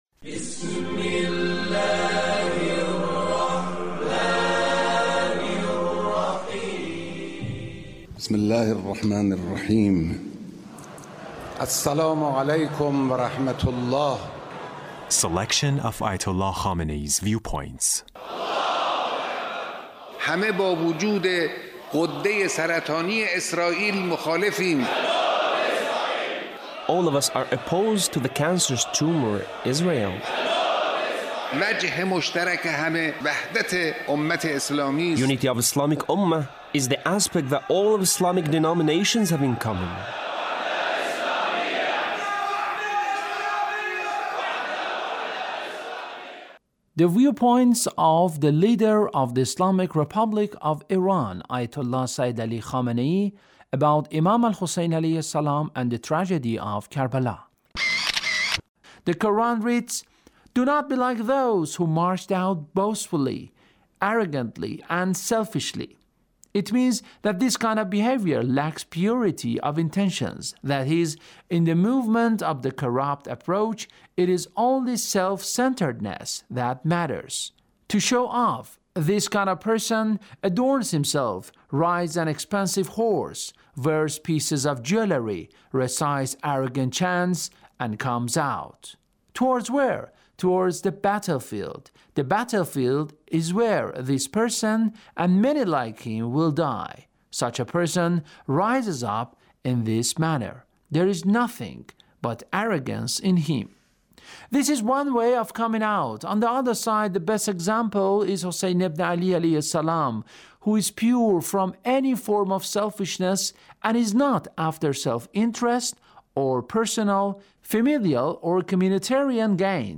Leader's Speech (1785)